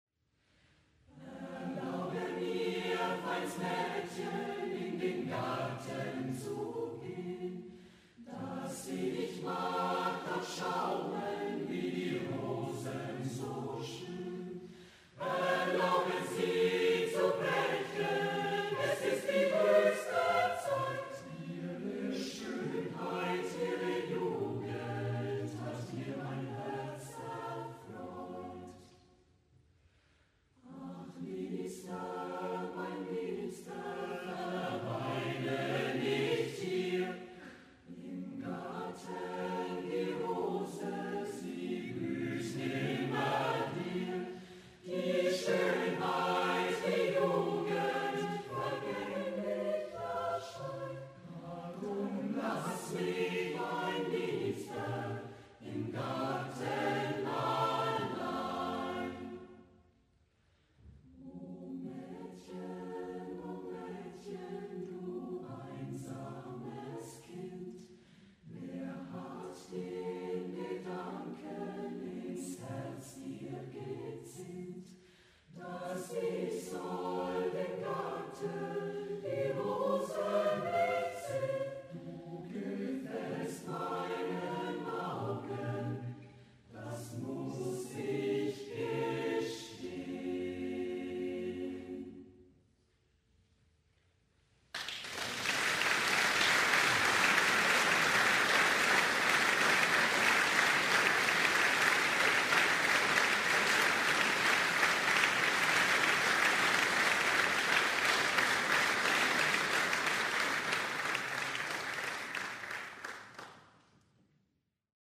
CHORios - Chor der städtischen Musikschule Gundelfingen
Mitschnitt des Chorverband Bayerisch Schwaben:
Ende 2014 hat CHORios am CBS Wertungssingen in der Bayerische Musikakademie Marktoberdorf mit hervorragendem Erfolg teilgenommen.